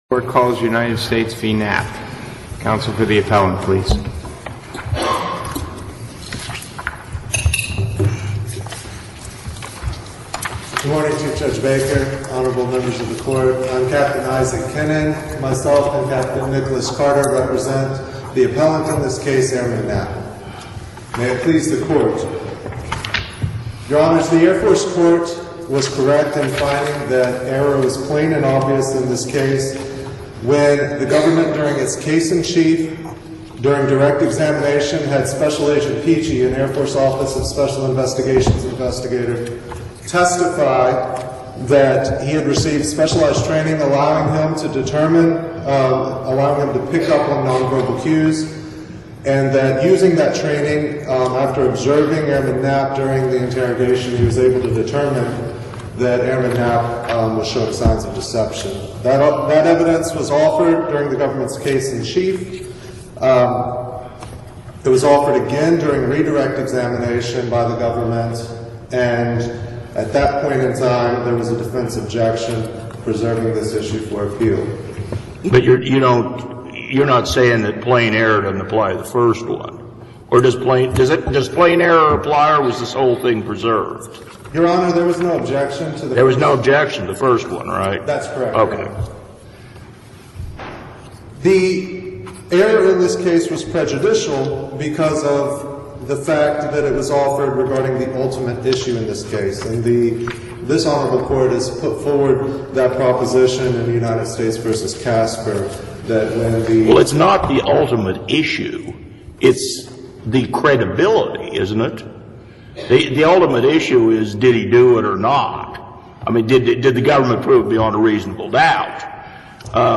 NOTE: Counsel for each side will be allotted 20 minutes to present oral argument.